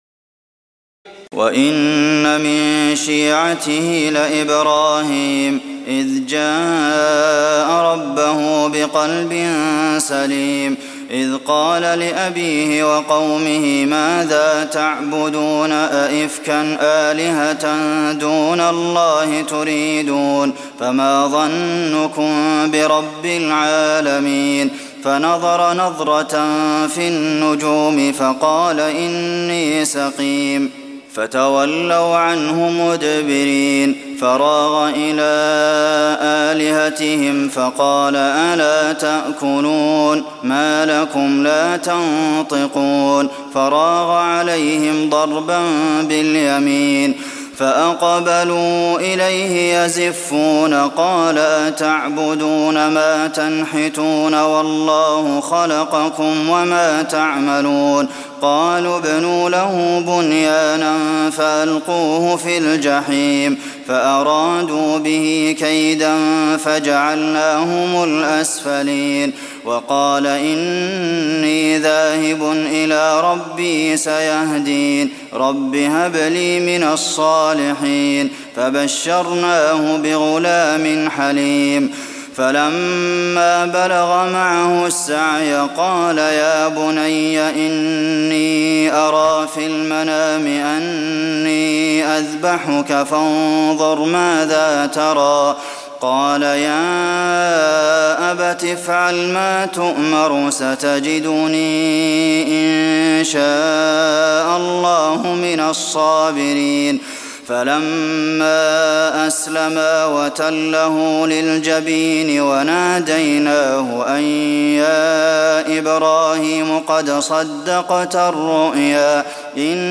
تراويح ليلة 22 رمضان 1423هـ من سور الصافات (83-182) وص (1-49) Taraweeh 22 st night Ramadan 1423H from Surah As-Saaffaat and Saad > تراويح الحرم النبوي عام 1423 🕌 > التراويح - تلاوات الحرمين